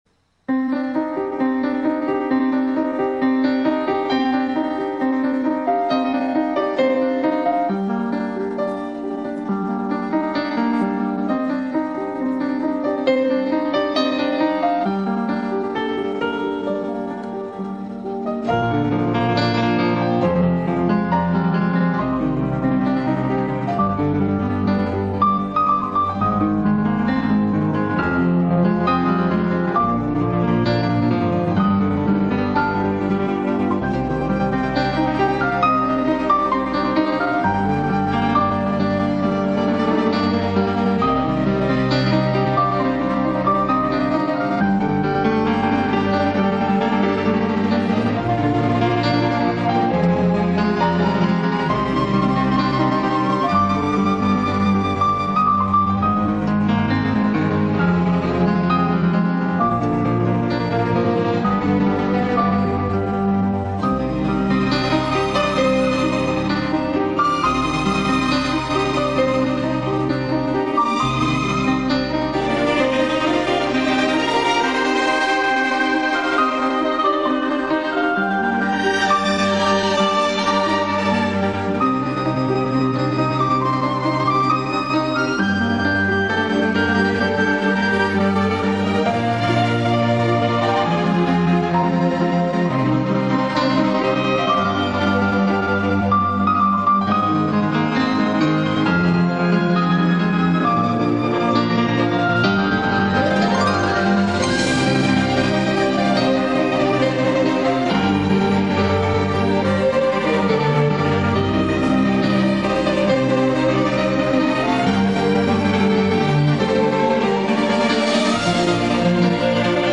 Kuas Cat Sound Effects Free Download